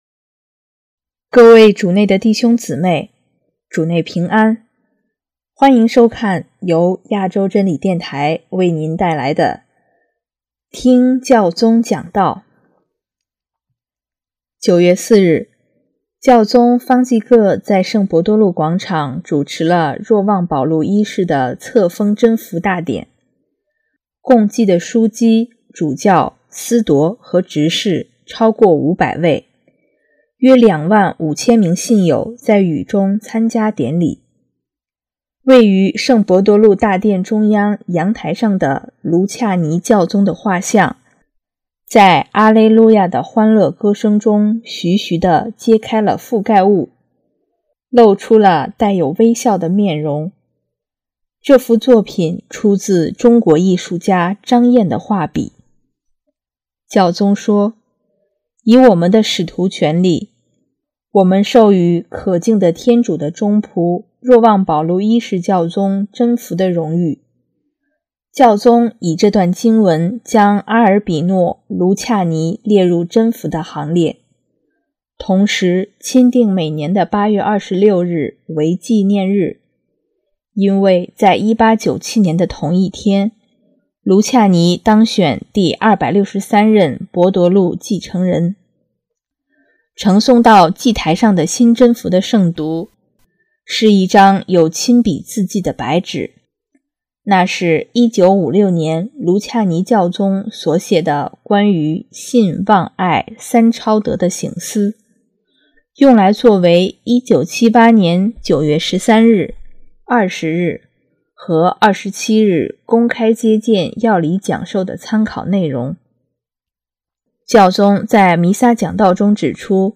9月4日，教宗方济各在圣伯多禄广场主持了若望保禄一世的册封真福大典，共祭的枢机、主教、司铎和执事超过500位，约2万5千名信友在雨中参加典礼。